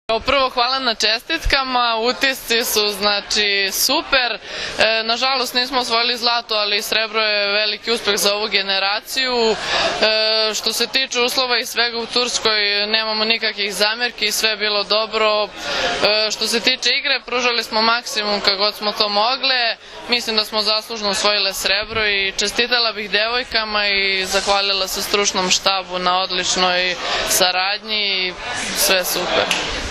Na beogradskom aerodromu “Nikola Tesla”, srebrne juniorke su sa cvećem dočekali predstavnici Odbojkaškog saveza Srbije.
IZJAVA